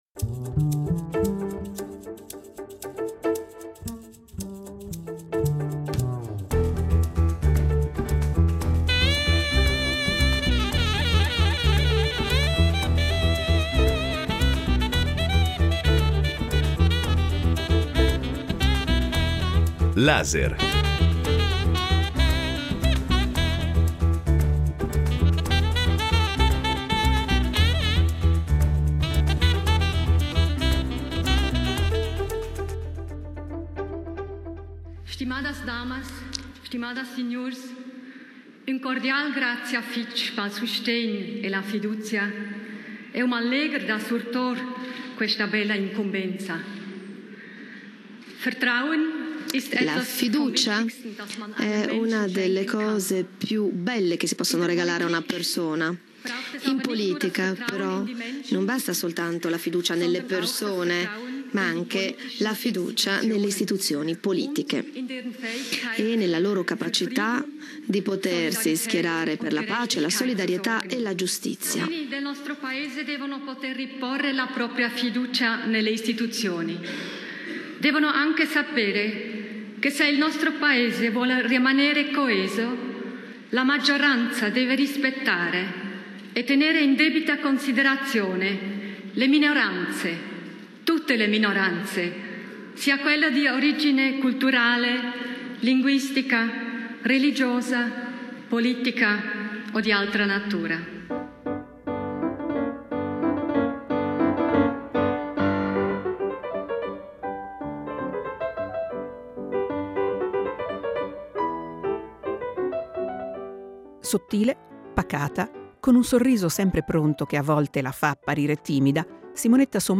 Incontro con Simonetta Sommaruga